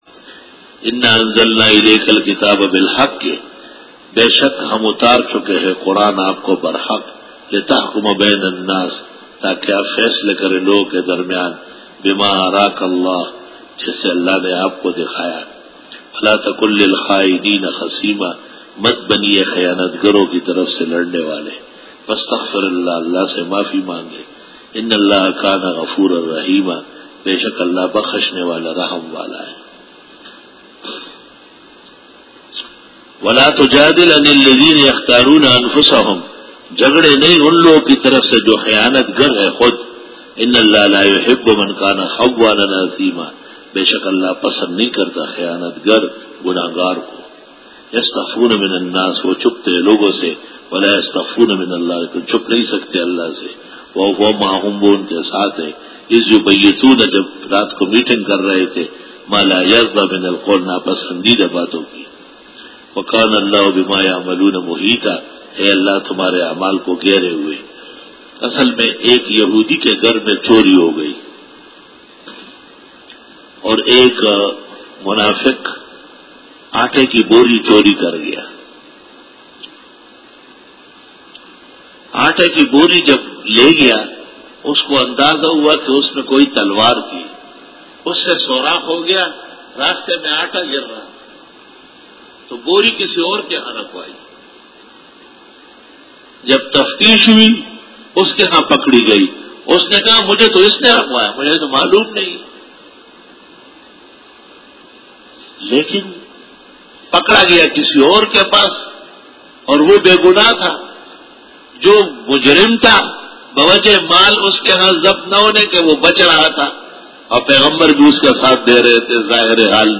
سورۃ النساء-رکوع-16 Bayan